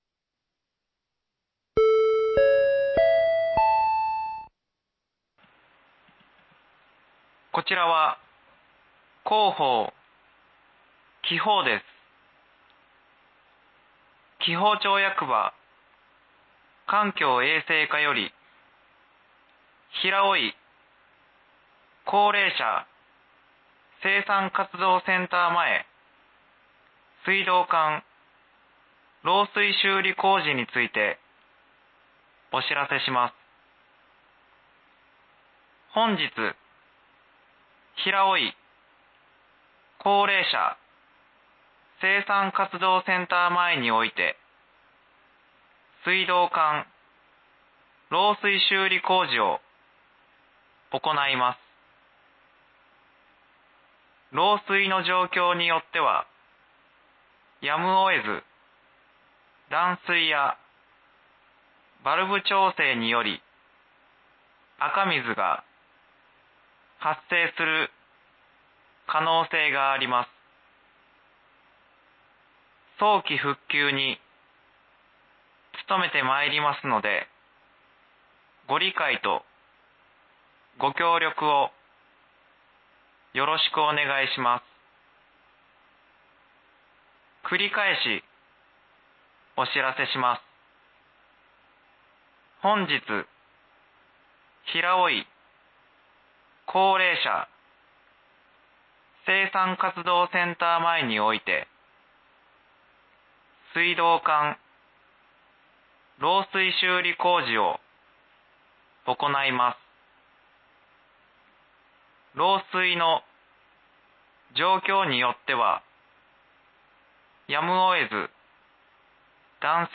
（平尾井地区のみ放送）
放送音声